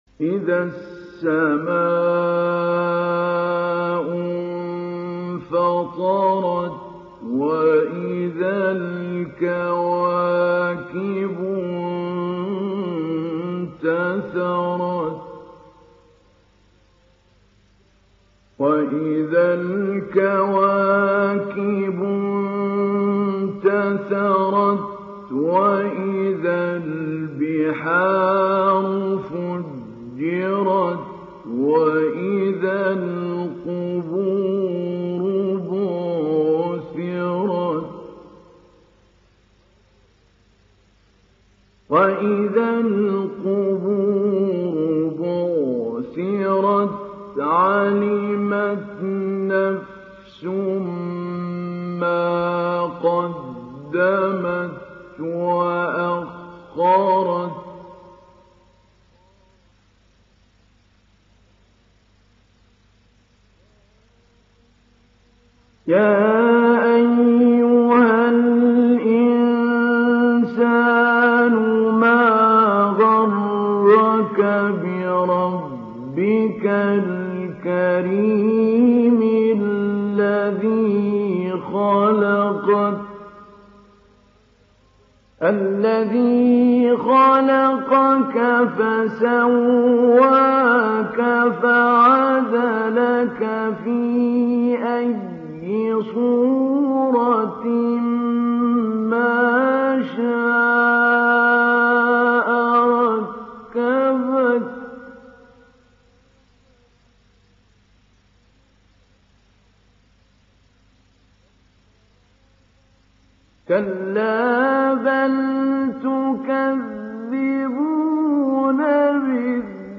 دانلود سوره الانفطار mp3 محمود علي البنا مجود روایت حفص از عاصم, قرآن را دانلود کنید و گوش کن mp3 ، لینک مستقیم کامل
دانلود سوره الانفطار محمود علي البنا مجود